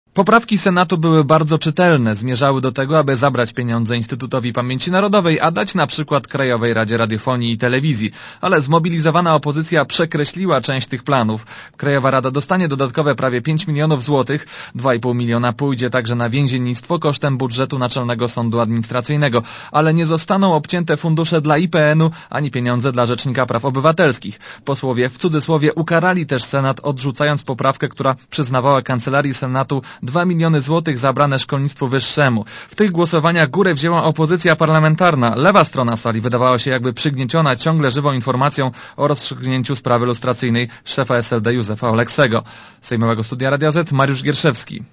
Relacja reportera Radia Zet Posłowie zdecydowali, że przyszłoroczne wydatki Krajowej Rady Radiofonii i Telewizji będą wieksze o ponad 4,5 mln zł od obecnych.